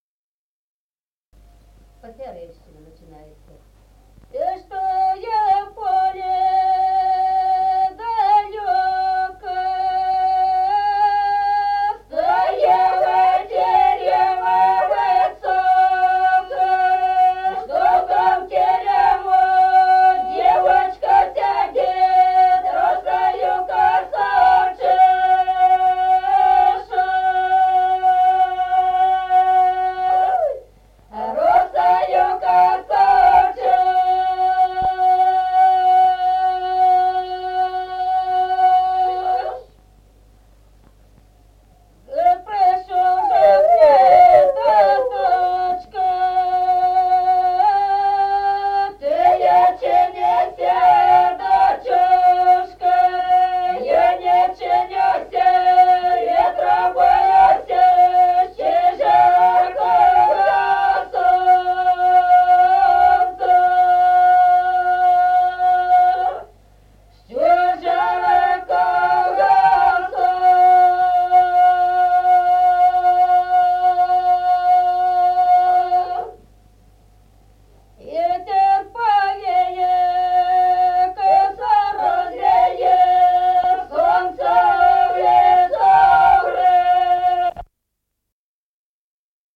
Народные песни Стародубского района «И чтой в поле далёко», свадебная.
1953 г., с. Остроглядово.